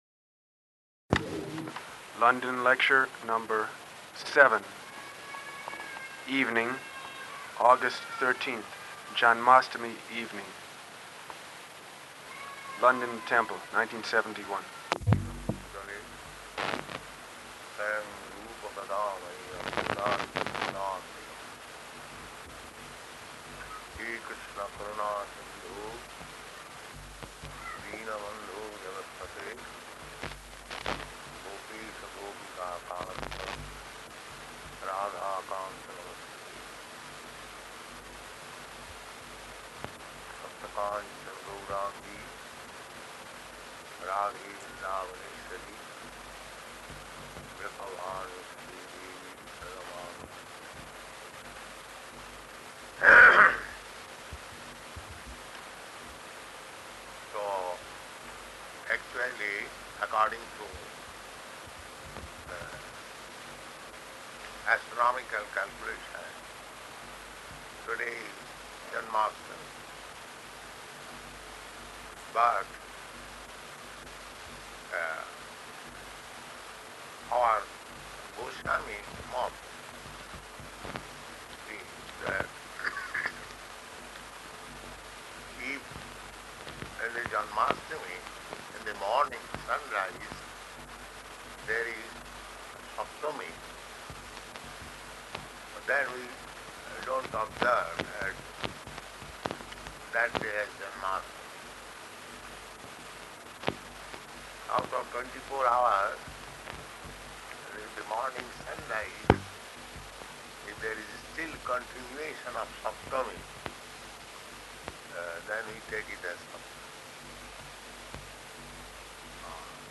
Janmāṣṭamī Lecture
Janmāṣṭamī Lecture --:-- --:-- Type: Lectures and Addresses Dated: August 13th 1971 Location: London Audio file: 710813L2-LONDON.mp3 Devotee: [introducing tape] London lecture number 7.
London Temple, 1971.